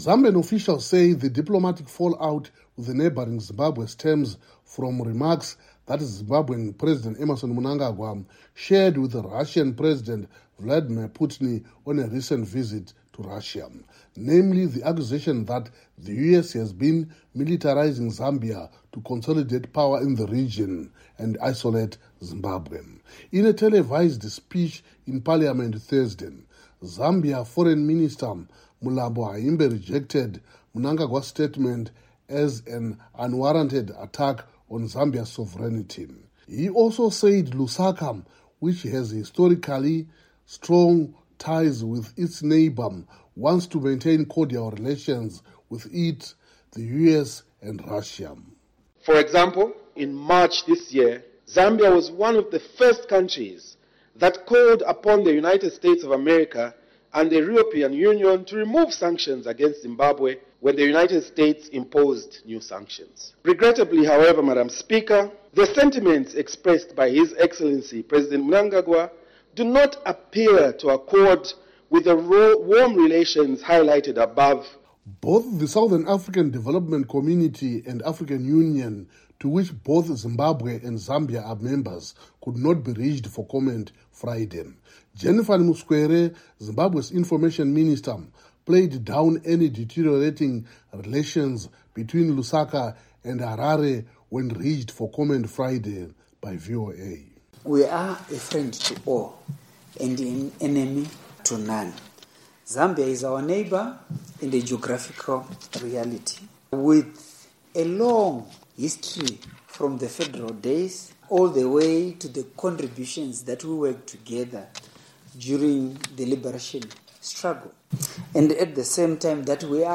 reports from Harare